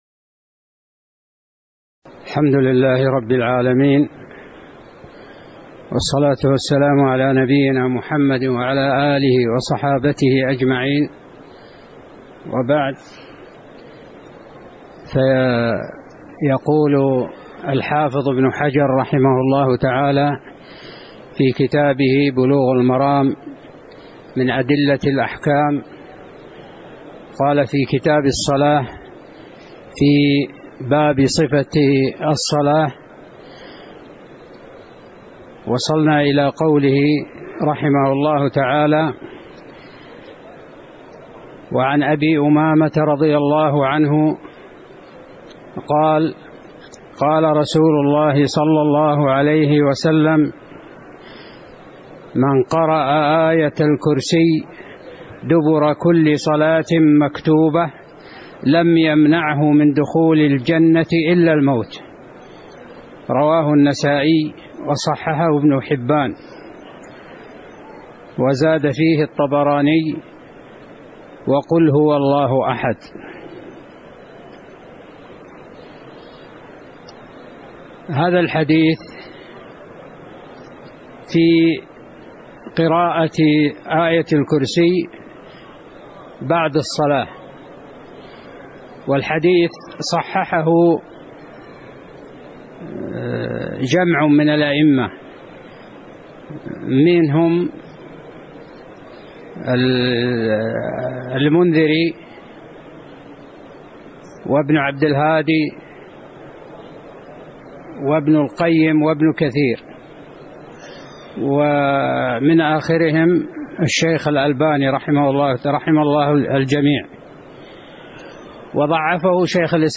تاريخ النشر ٢٥ صفر ١٤٣٩ هـ المكان: المسجد النبوي الشيخ